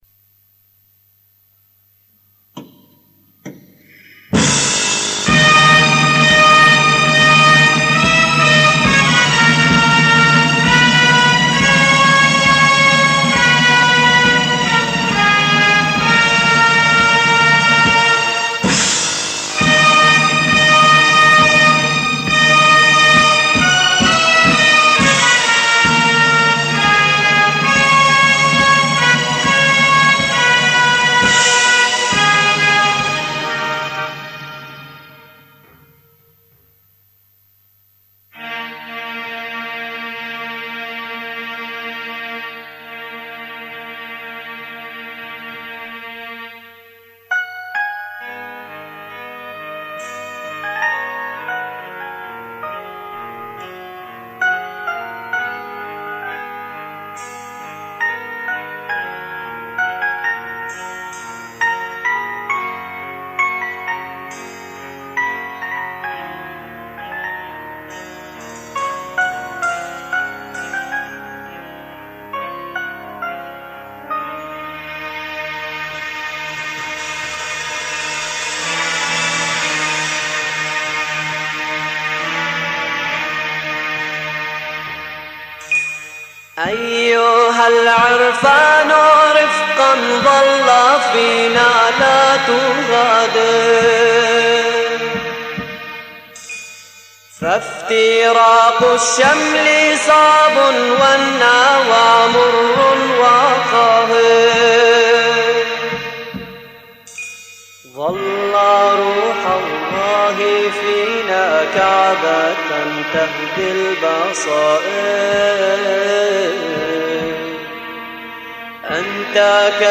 أناشيد لبنانية .... أيها العرفان